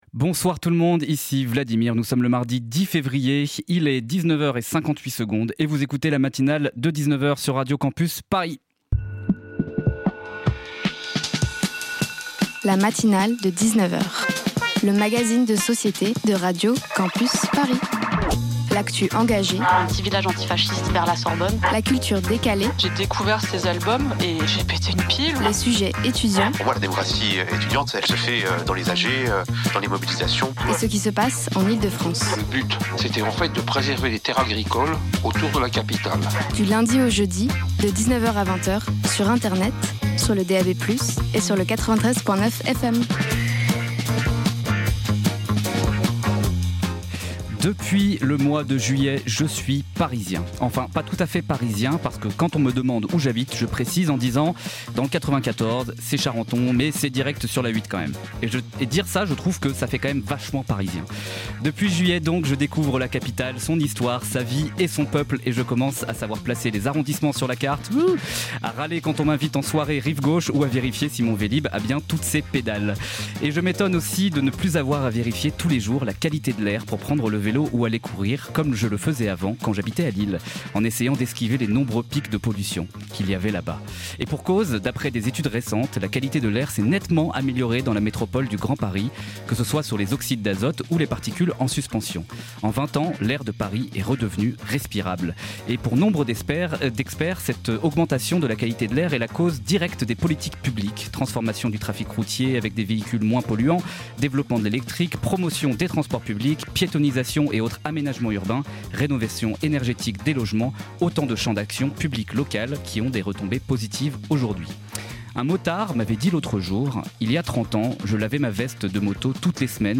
Type Magazine Société Culture